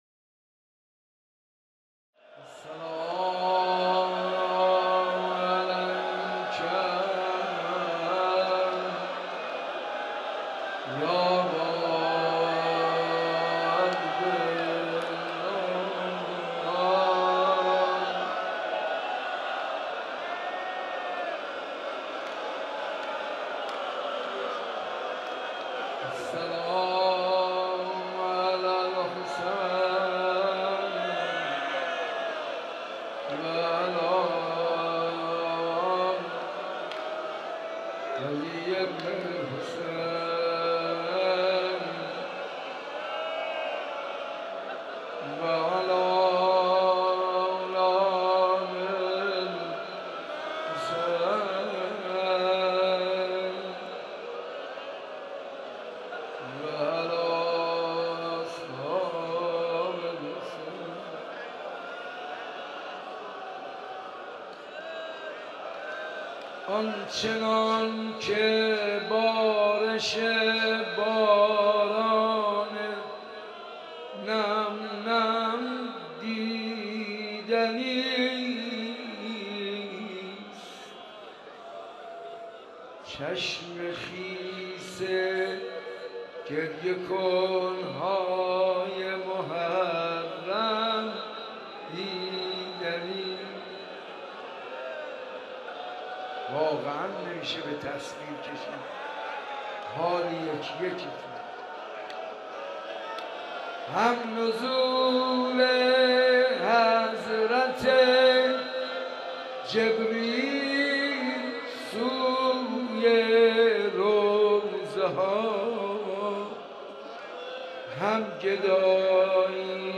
در مراسمی که به مناسبت شب دهم محرم در مسجد ارک تهران برگزار شد مرثیه‌خوانی کرد.